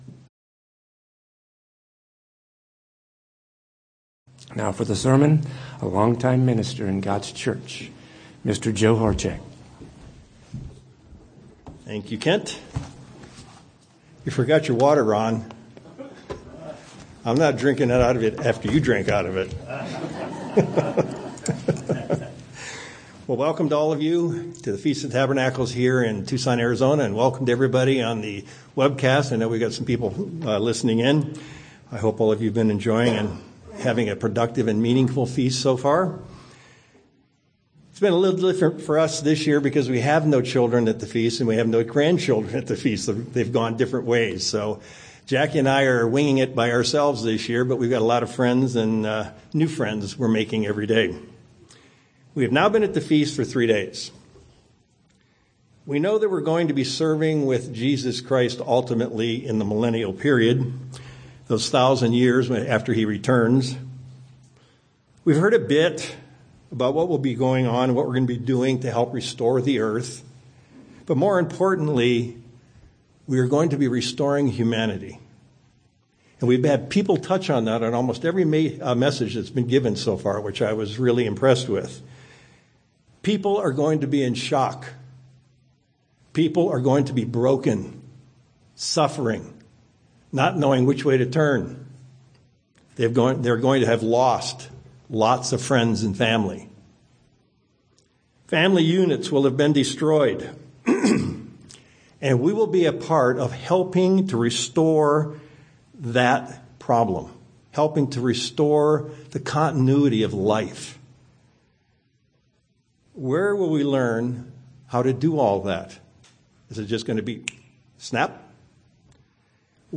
Sermons
Given in Tucson, AZ